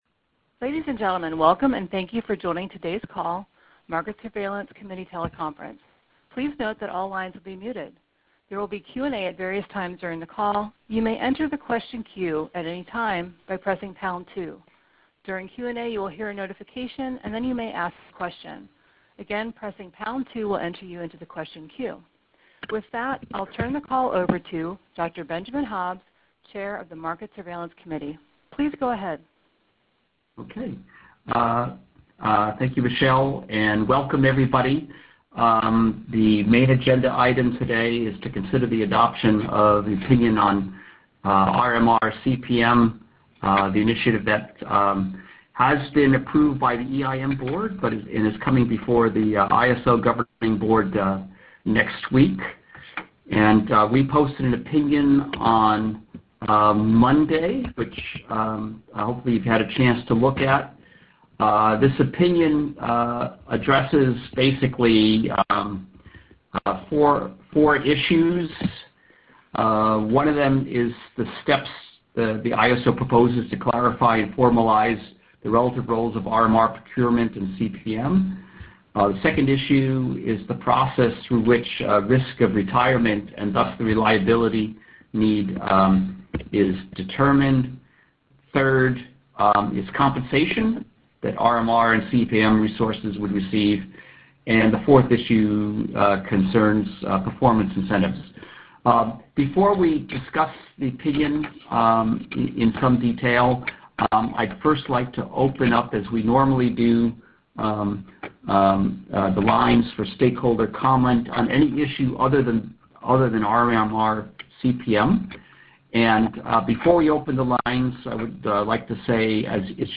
Audio - Market Surveillance Committee Teleconference – Mar 20, 2019